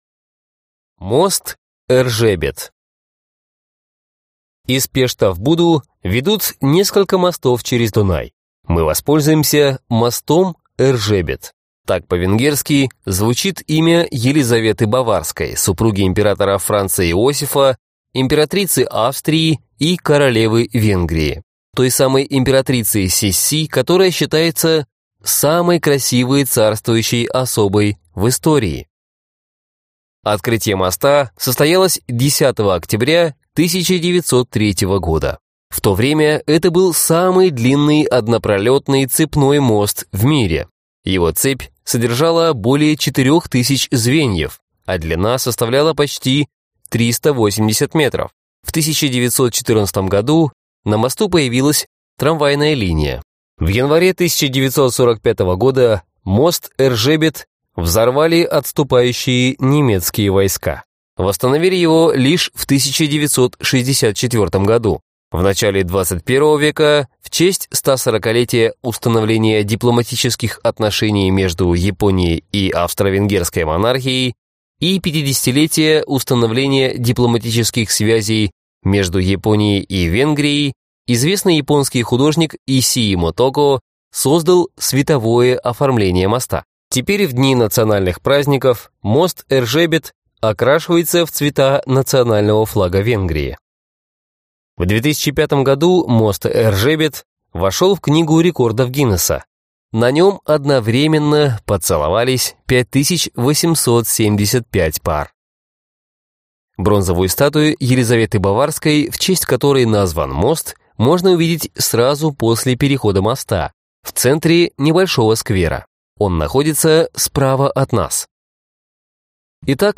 Аудиокнига Будапешт: Прогулка по Буде.